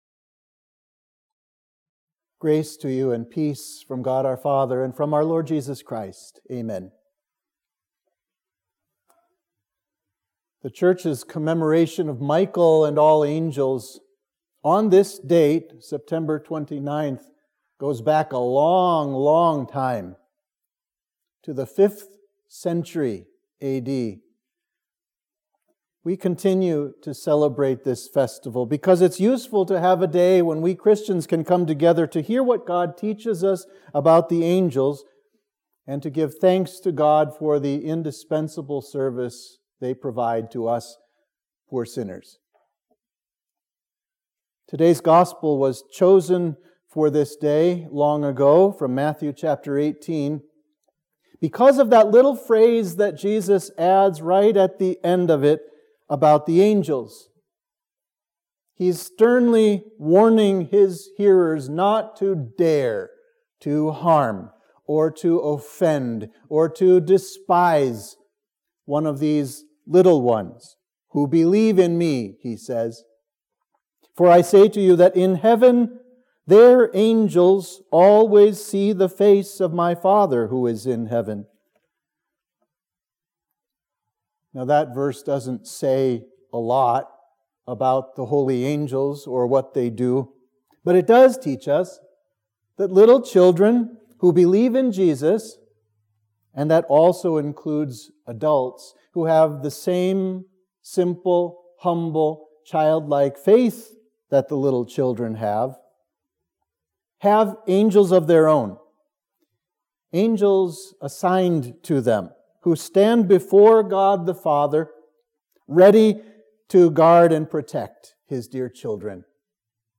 Sermon for the Festival of Michael & All Angels